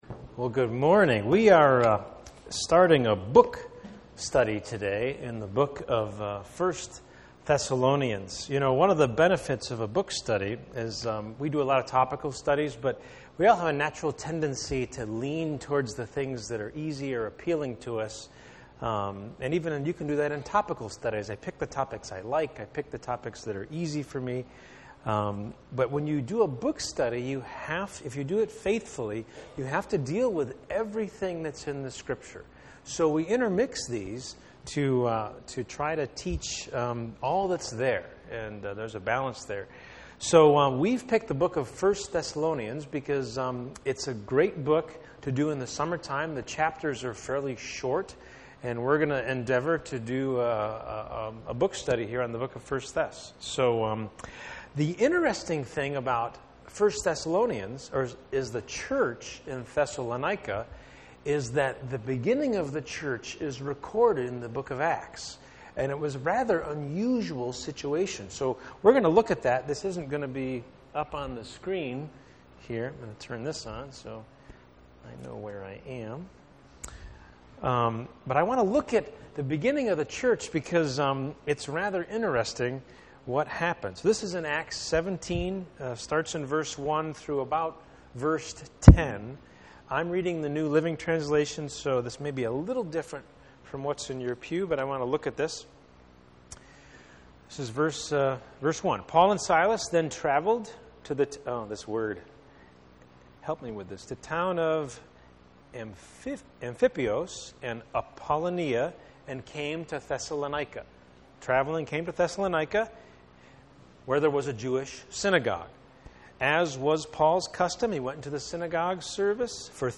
Passage: 1 Thessalonians 1:1-10 Service Type: Sunday Morning